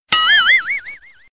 Comedy Sound Effects MP3 Download Free - Quick Sounds